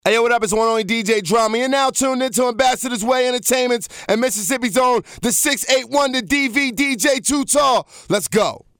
LIVE DROP